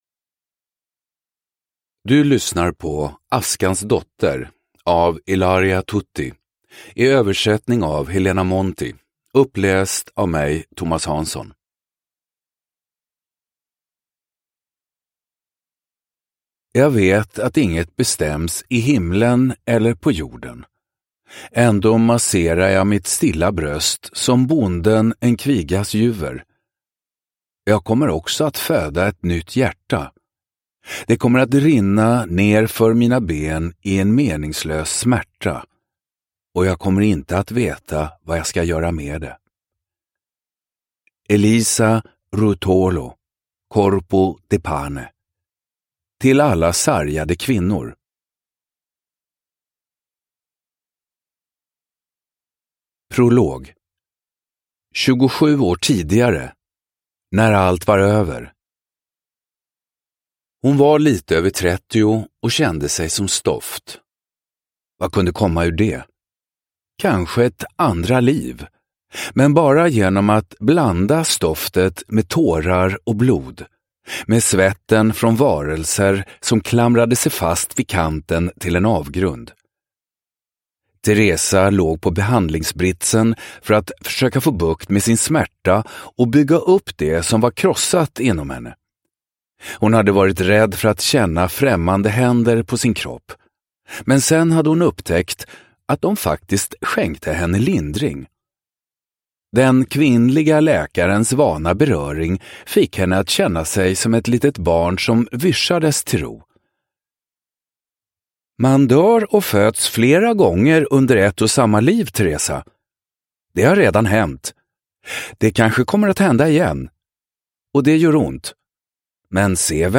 Askans dotter – Ljudbok – Laddas ner
Uppläsare: Thomas Hanzon